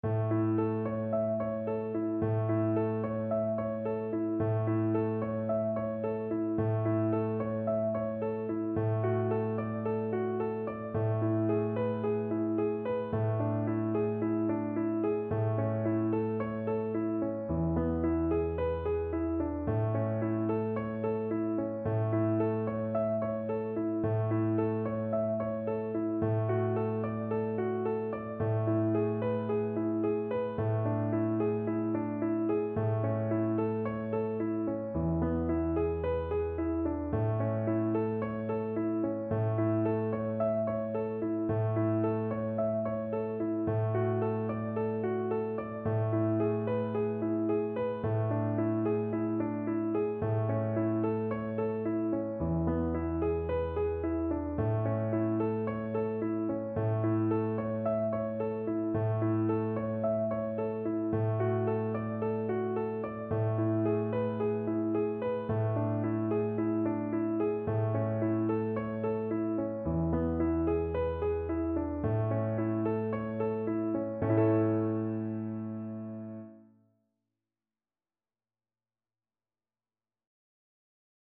Violin
A major (Sounding Pitch) (View more A major Music for Violin )
Allegro Moderato =c.110 (View more music marked Allegro)
4/4 (View more 4/4 Music)
Traditional (View more Traditional Violin Music)
Venezuelan